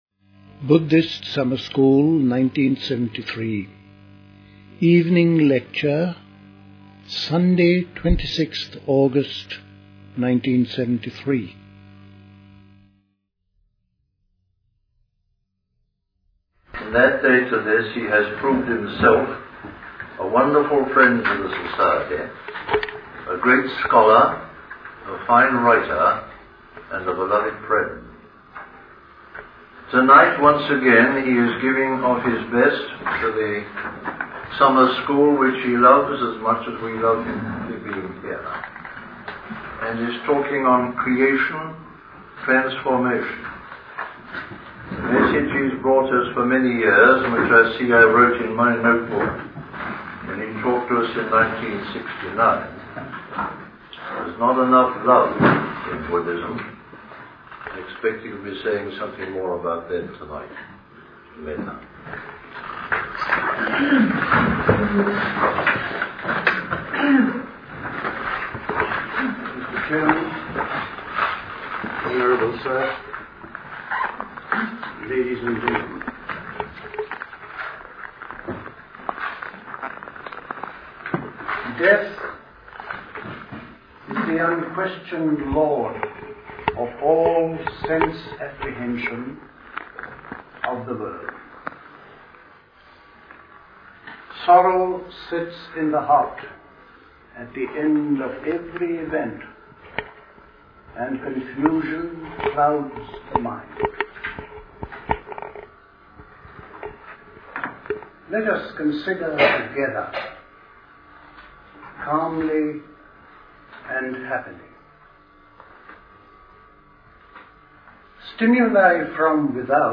Recorded at the 1973 Buddhist Summer School. Introduced by Christmas Humphreys.